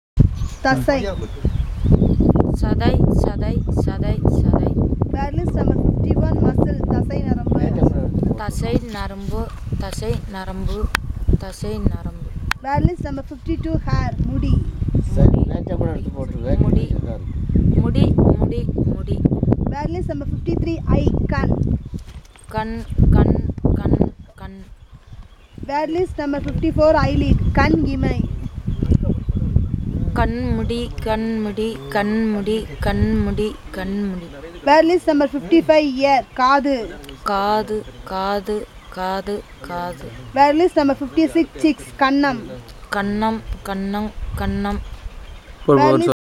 NotesThis is an elicitation of words about human body parts, using the SPPEL Language Documentation Handbook.